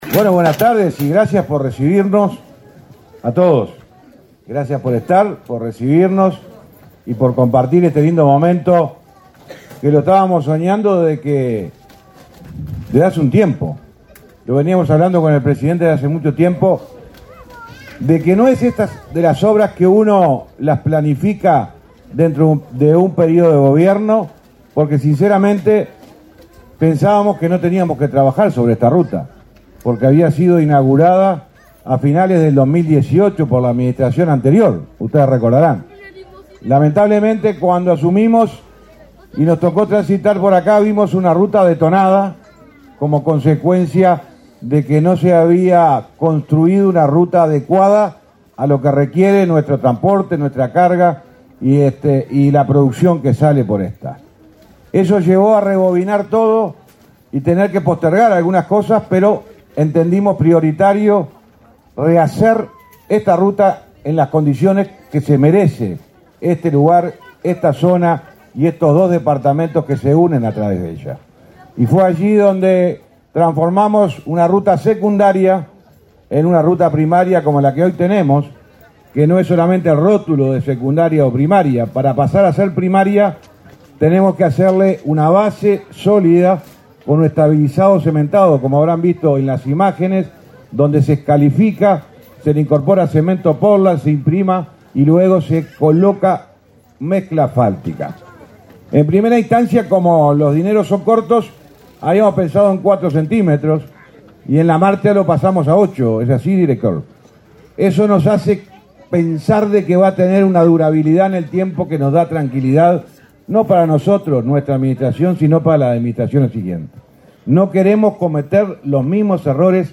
Palabras del ministro de Transporte y Obras Públicas, José Luis Falero
Palabras del ministro de Transporte y Obras Públicas, José Luis Falero 09/10/2024 Compartir Facebook X Copiar enlace WhatsApp LinkedIn En la ceremonia de inauguración de obras realizadas en la ruta n.º 30, en el tramo comprendido entre Artigas y Tranqueras, se expresó el ministro de Transporte y Obras Públicas, José Luis Falero.